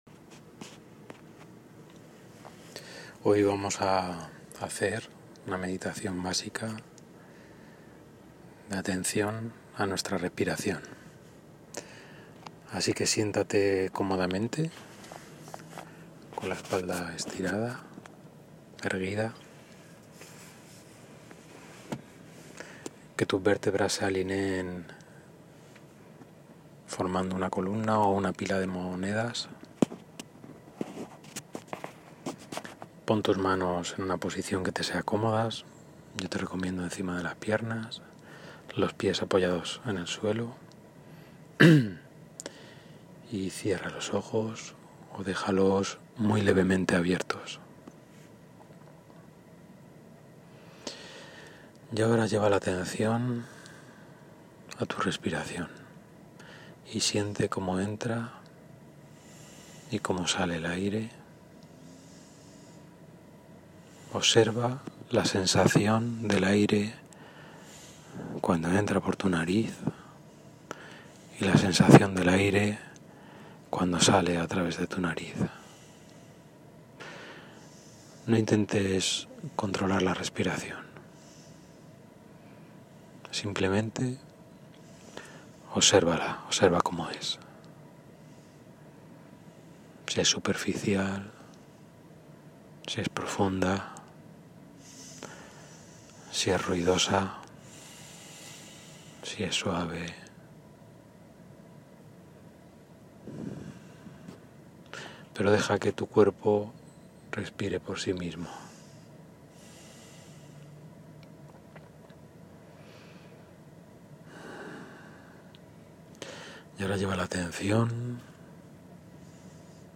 Consiste en una meditación en nuestro cuerpo, en las distintas sensaciones que podamos tener, pero que se realiza de manera dirigida. Vamos a ir posando nuestra atención sobre cada una de las partes de nuestro cuerpo.
Meditación-básica-15-minutos-.m4a